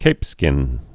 (kāpskĭn)